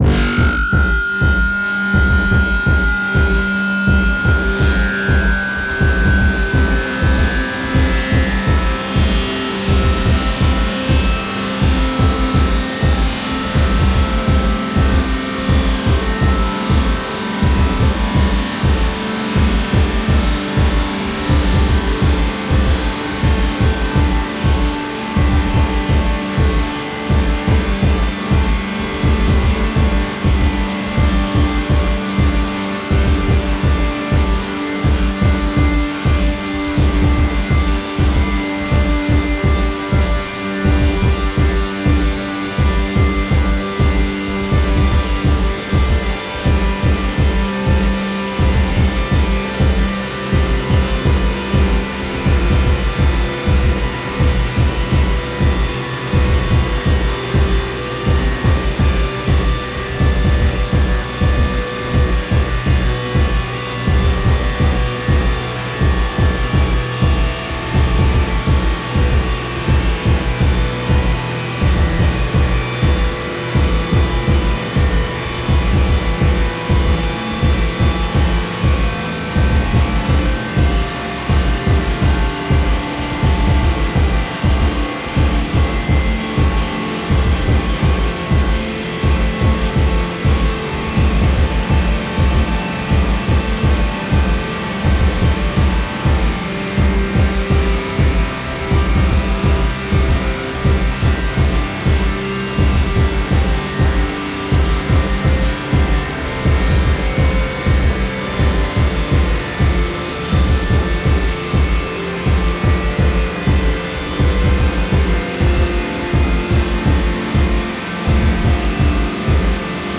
大地を轟かす太く重い狼の”鼓動”。
天と地を結ぶ霊獣の為の、美しく力強い祝祭曲。
たいへんデリケートで複雑なパンニングと、スペクトル分布を特徴とするアルバムです。
現代音楽、先端的テクノ、実験音楽をお好きな方々にはもちろん、ギター愛好家の方々にもお薦めのアルバムです。